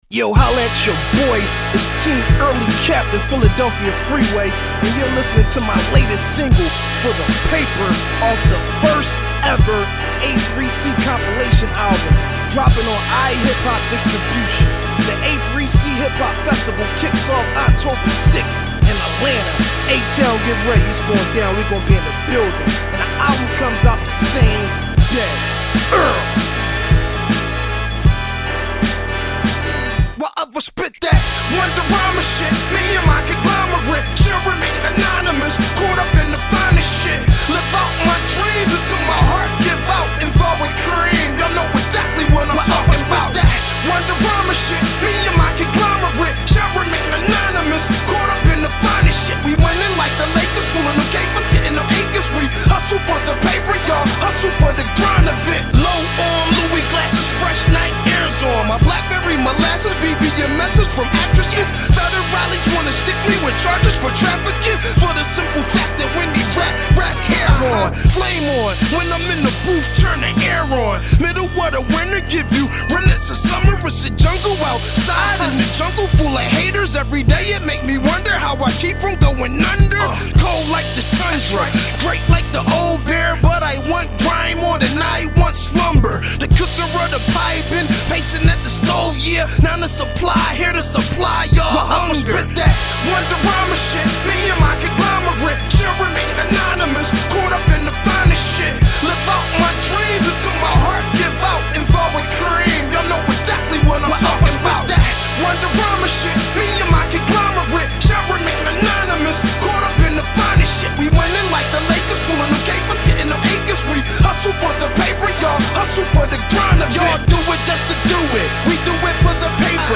lush, vibrant Latin horns